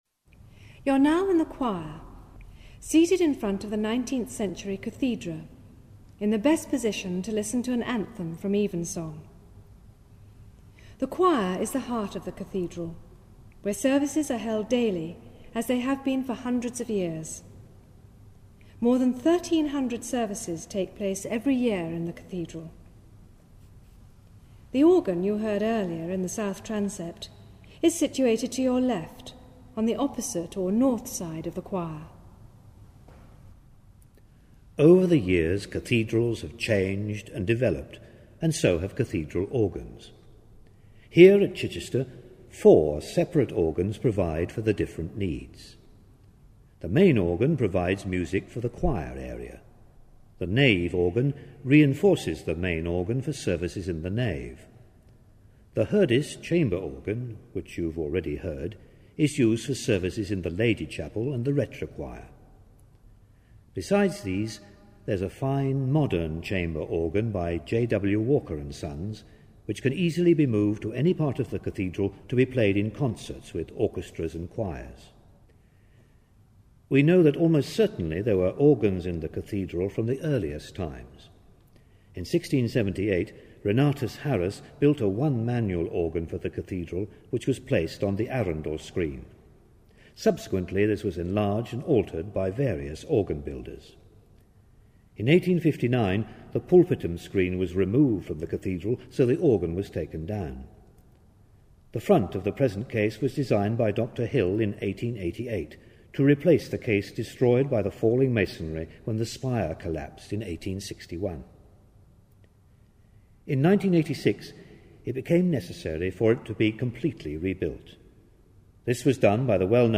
An Acoustic Fingerprint Guide of Chichester Cathedral - 6: The Choir and an Anthem from Evensong
6-the-choir-with-the-anthem-from-evensong.mp3